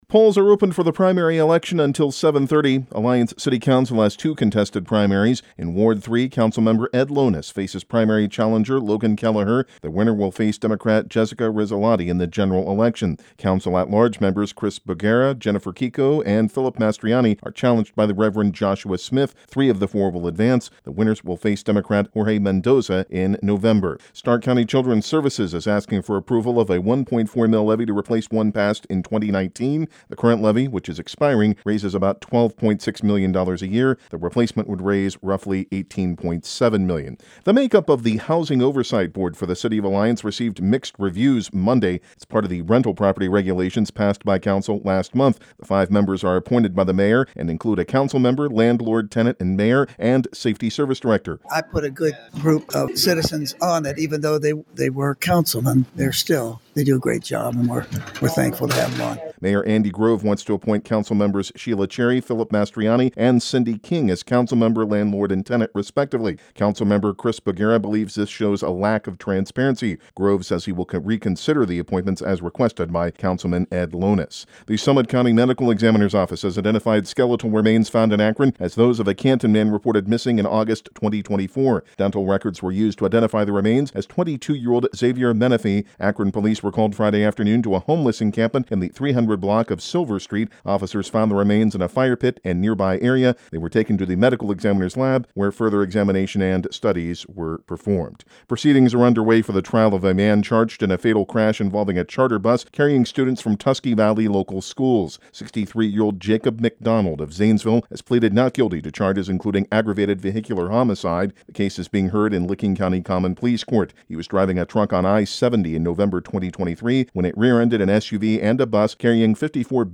Evening News